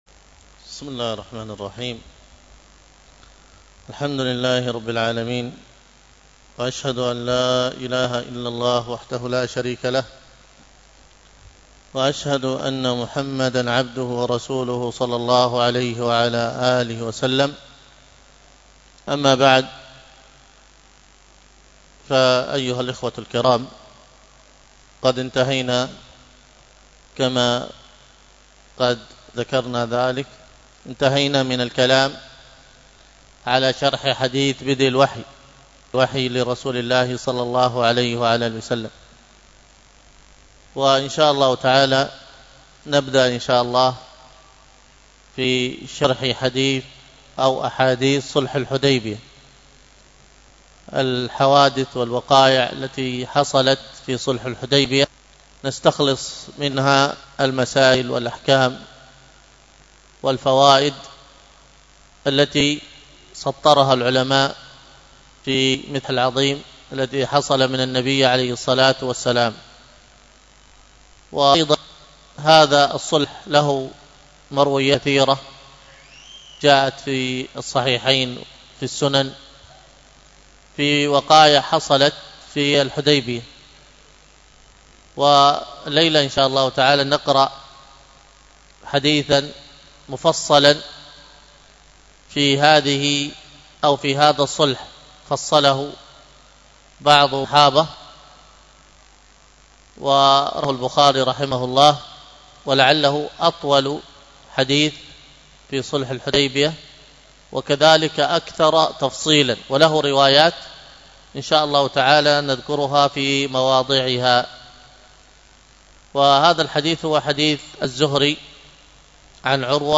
الدرس في العاديات، تلاوة من صلاة التراويح والقيام